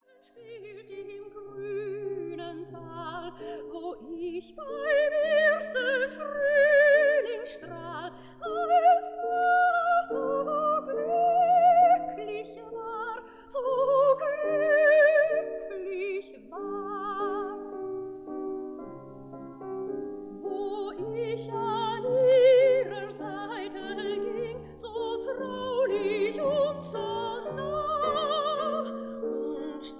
soprano
piano
Sofiensaal, Vienna